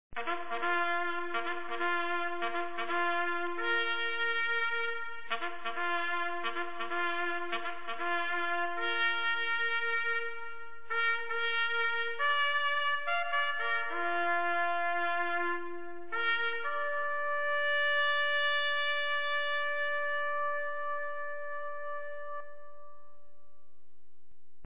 BUGLE CALLS
ROUSE
rouse_vr4q11.wav